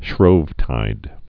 (shrōvtīd)